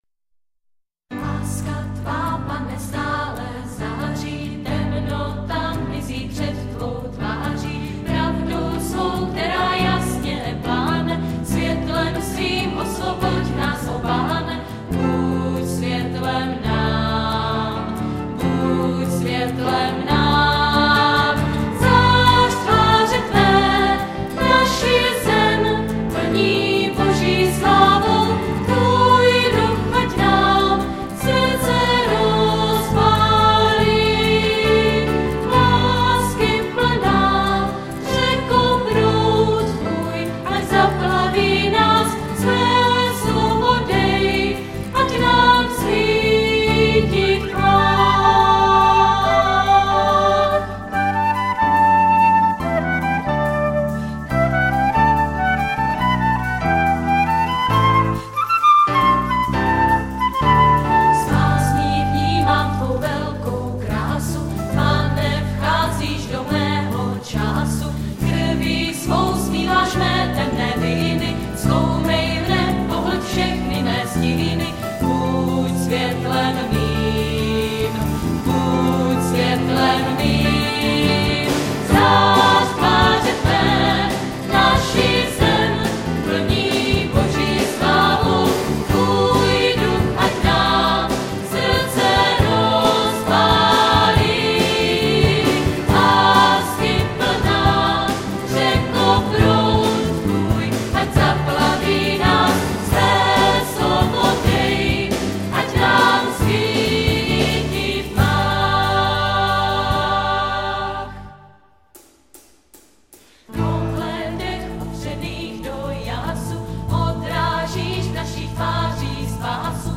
Nahráno 24.-26. června 2003 v modlitebně CČSH v Kroměříži.
Klíčová slova: písně, schóla,